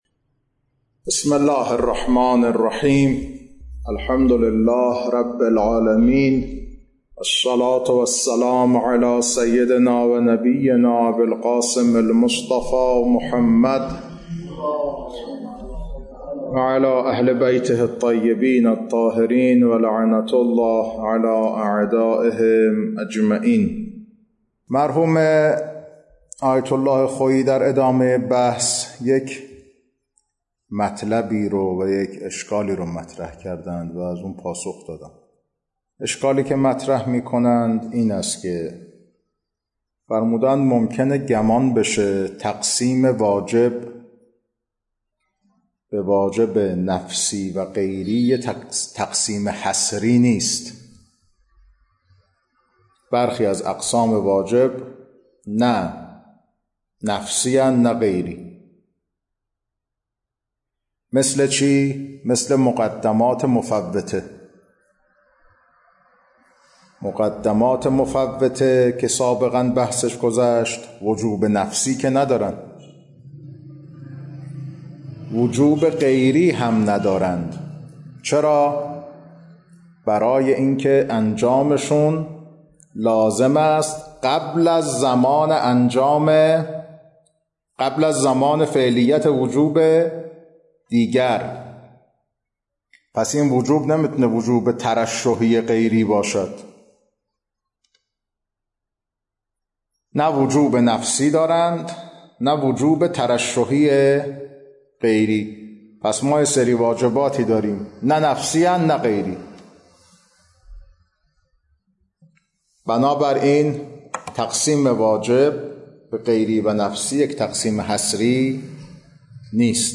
کلاس‌ها خارج اصول